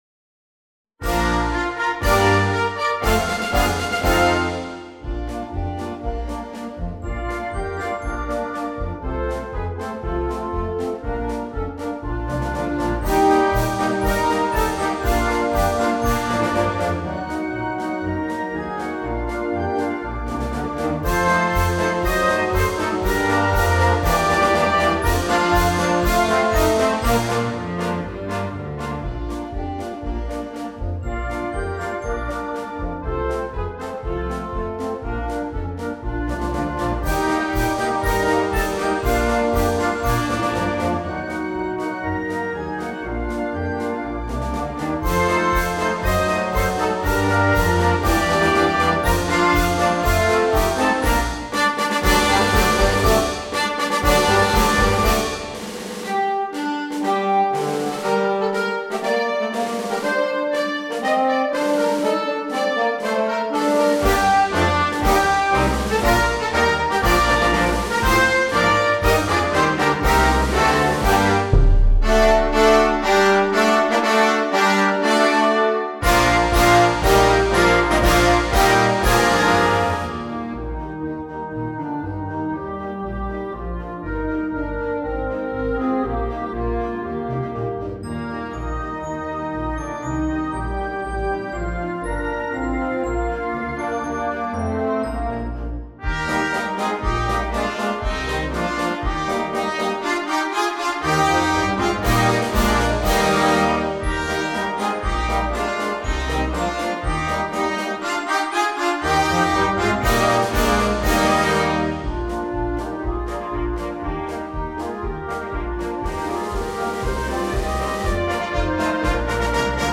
Gattung: Weihnachtsmedley für Blasorchester
Besetzung: Blasorchester